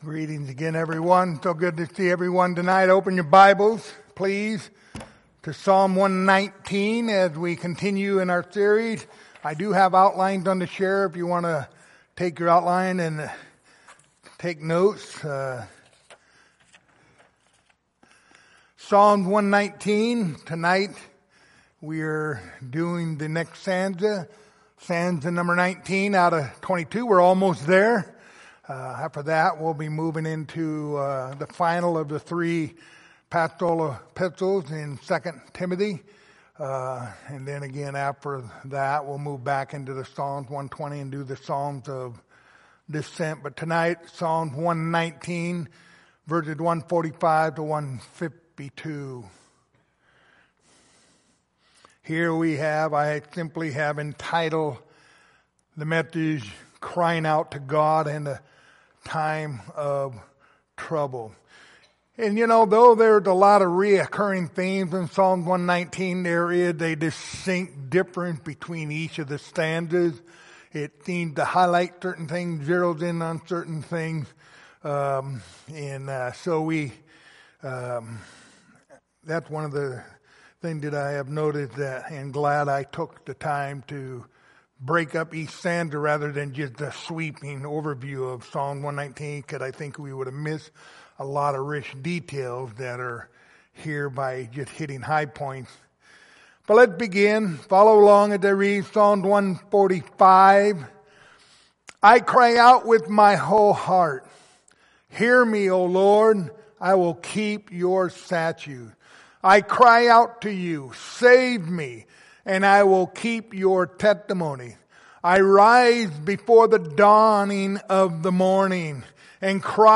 Passage: Psalm 119:145-152 Service Type: Sunday Evening